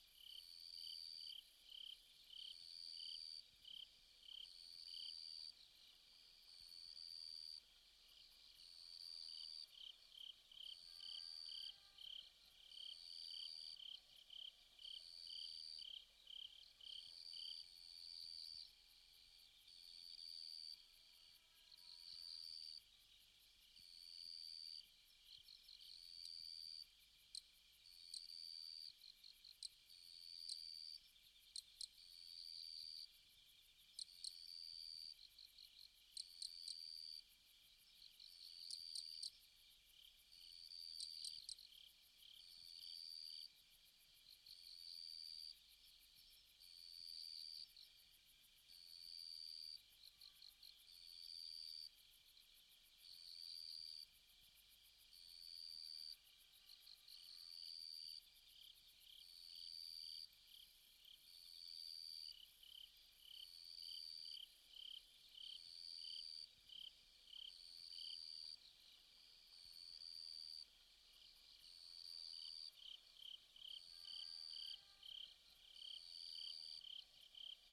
sfx_夜晚虫鸣.ogg